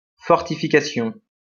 IPA/fɔʁ.ti.fi.ka.sjɔ̃/